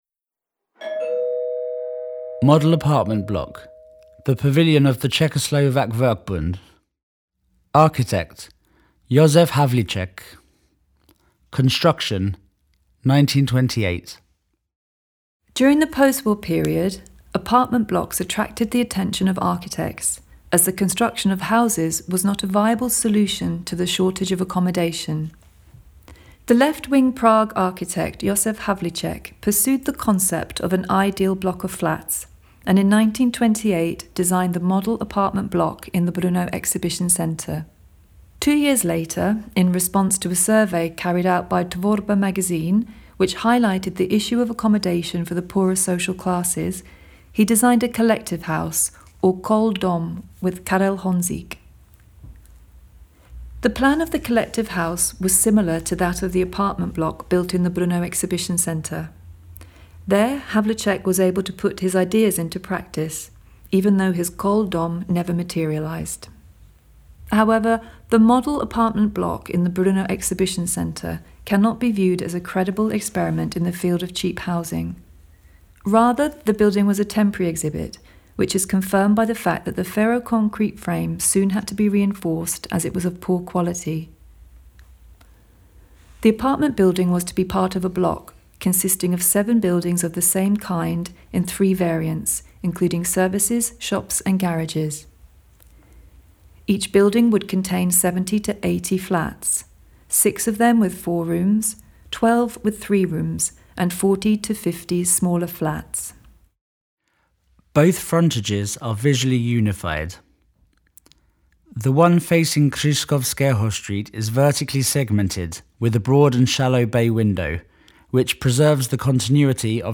Výstavba areálu 1928–1968 , Brno 1968, p. s.84–85 F Play audio guide ( Download MP3 ) Audio can't be play